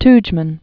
(tjmən), Franjo 1922-1999.